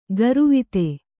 ♪ garuvite